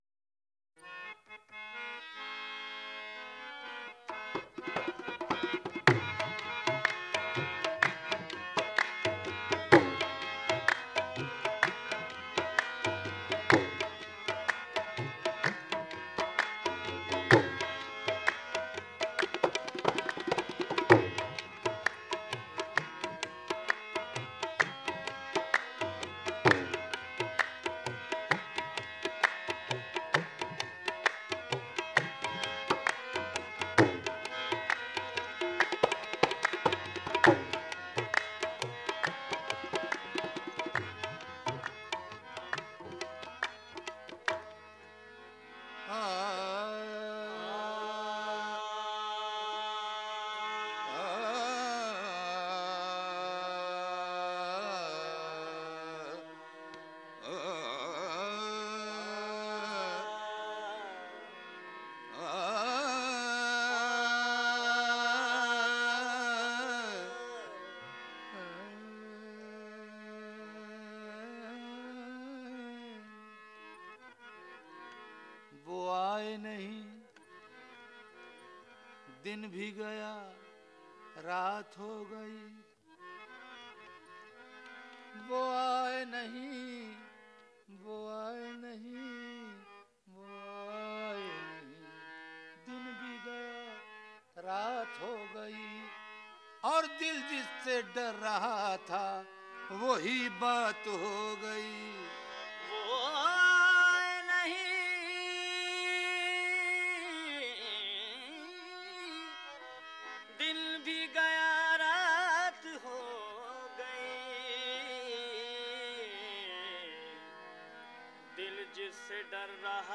Sufi Music